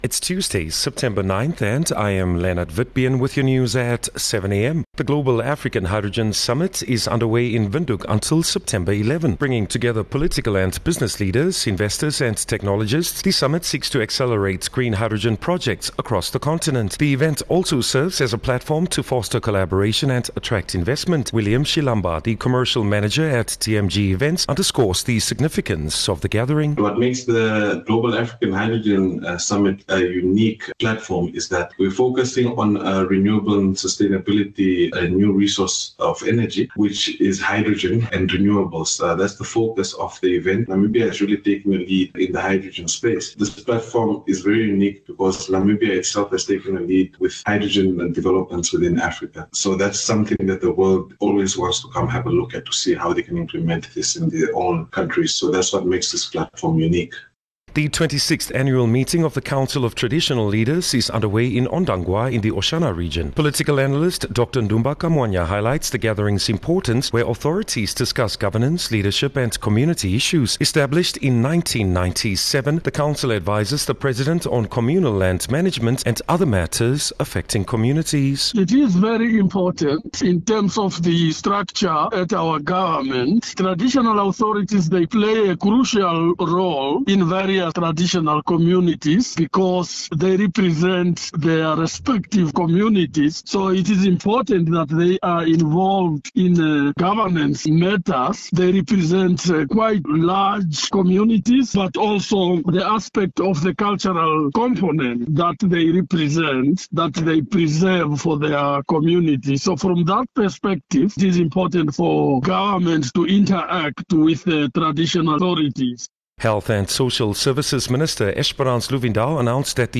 9 Sep 9 September-7am news